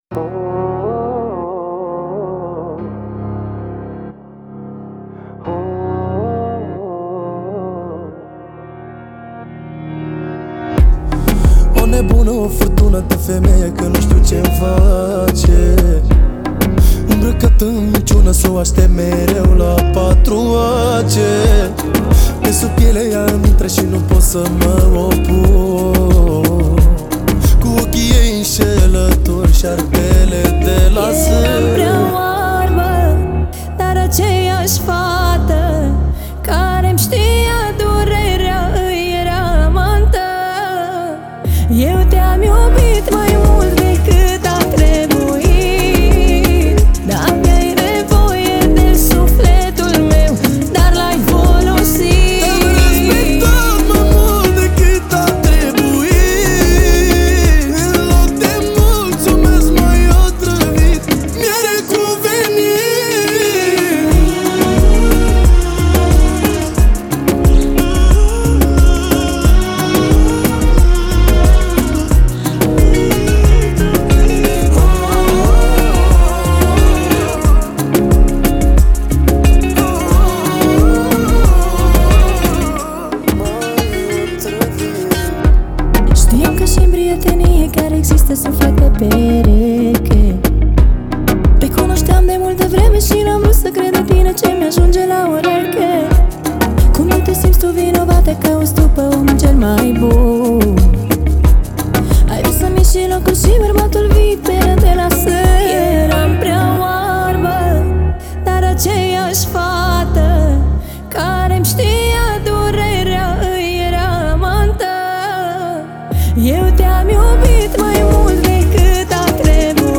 это зажигательная песня в жанре поп-фолк